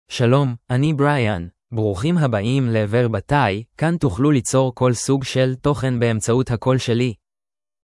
BrianMale Hebrew AI voice
Brian is a male AI voice for Hebrew (Israel).
Voice sample
Listen to Brian's male Hebrew voice.
Male
Brian delivers clear pronunciation with authentic Israel Hebrew intonation, making your content sound professionally produced.